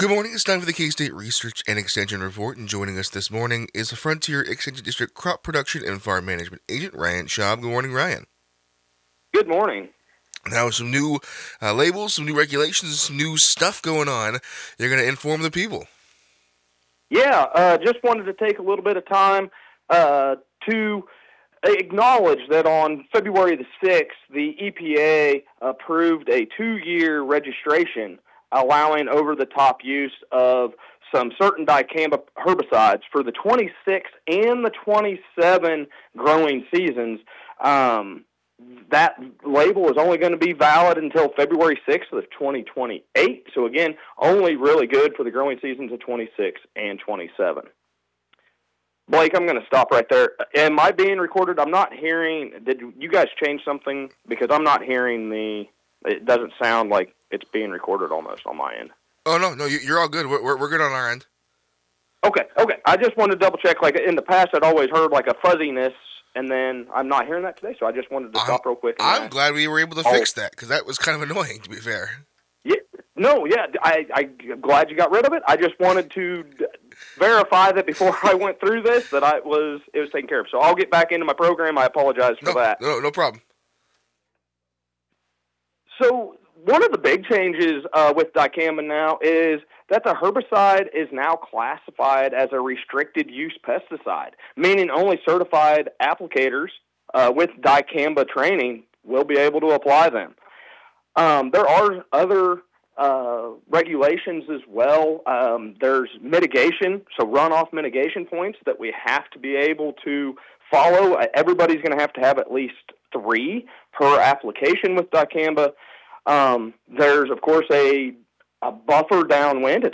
KOFO Radio 2026 Recordings – Local Broadcast Audio Archive